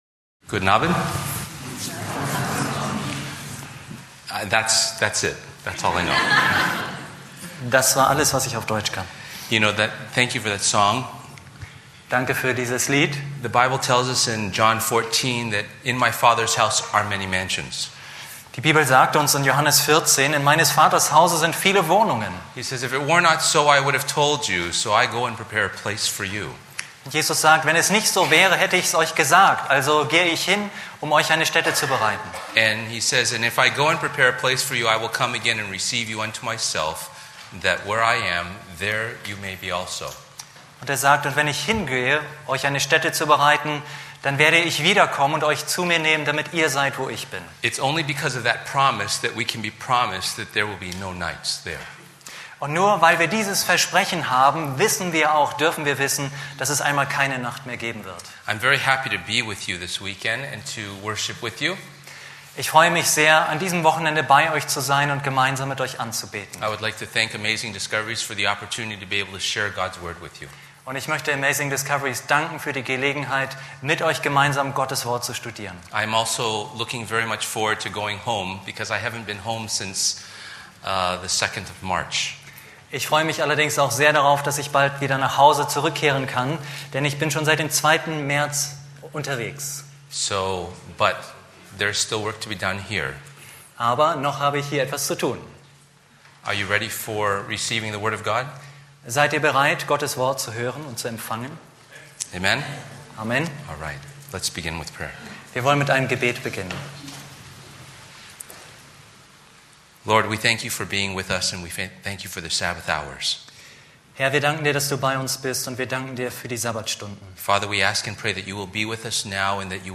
In seinen begeisternden Ansprachen steckt er mit seiner Liebe zu Jesus vor allem junge Leute an und macht Menschen Mut für ihre geistliche Reise.